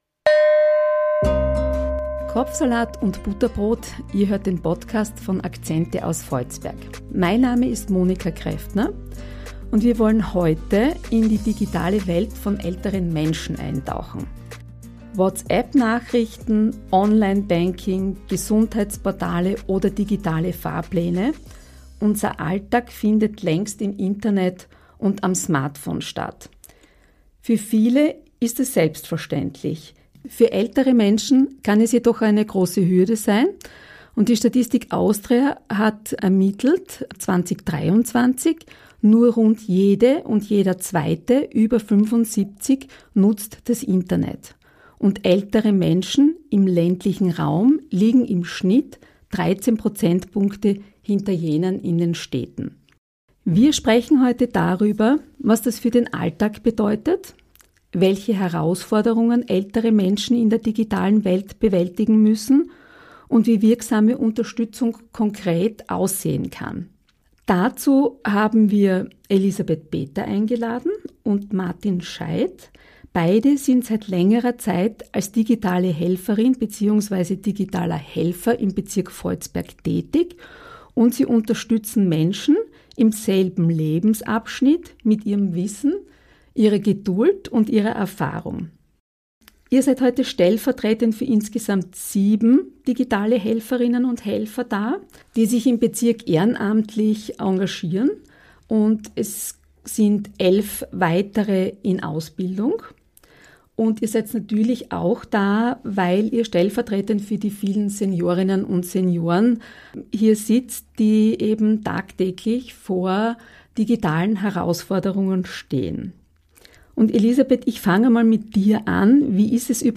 Zwei digitale Helfer:innen aus dem Bezirk Voitsberg über Lernen auf Augenhöhe, Alltagshürden am Smartphone und mehr digitale Sicherheit. Eine Folge über Selbstständigkeit und digitale Teilhabe.